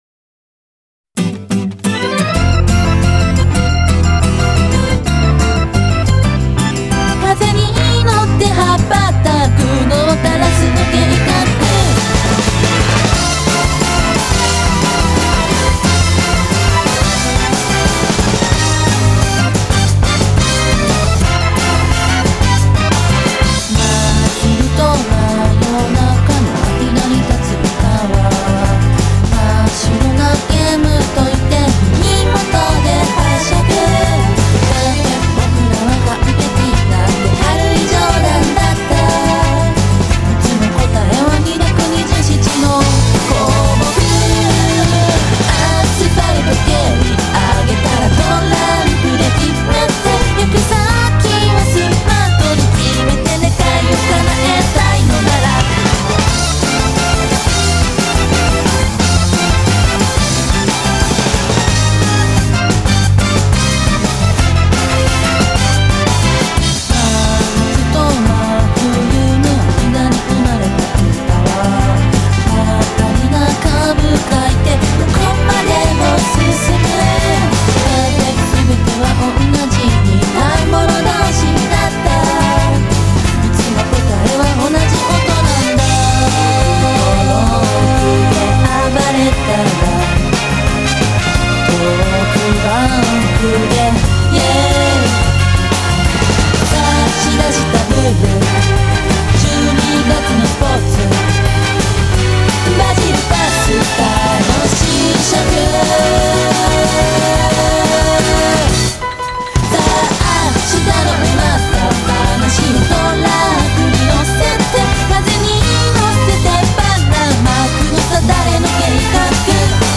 BPM178
Audio QualityPerfect (High Quality)
Genre: SOFT ROCK LONG.